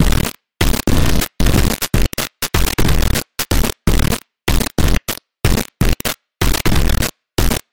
水晶碗 A4 1
描述：擦拭一个微湿的水晶碗。用A4调音。擦拭一个微湿的水晶碗。调到A4.
标签： 玻璃 vidro 晶体
声道立体声